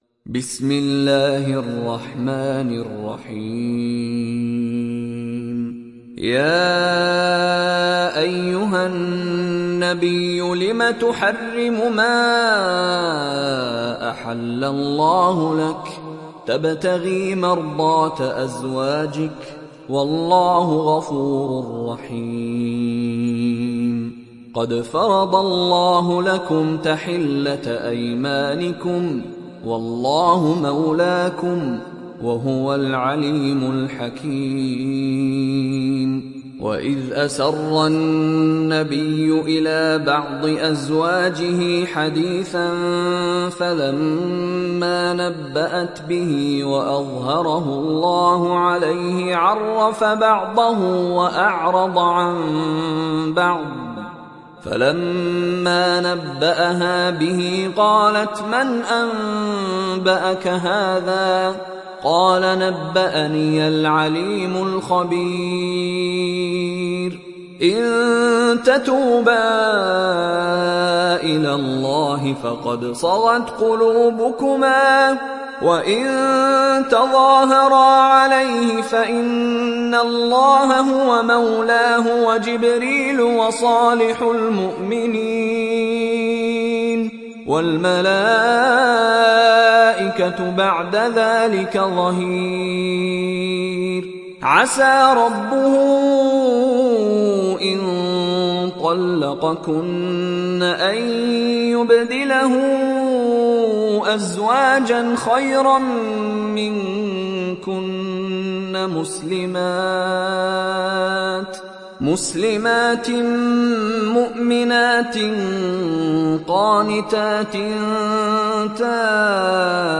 دانلود سوره التحريم mp3 مشاري راشد العفاسي روایت حفص از عاصم, قرآن را دانلود کنید و گوش کن mp3 ، لینک مستقیم کامل